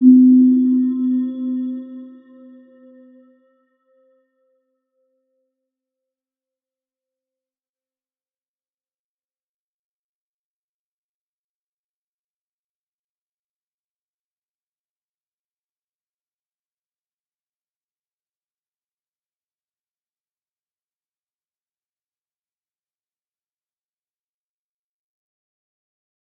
Round-Bell-C4-mf.wav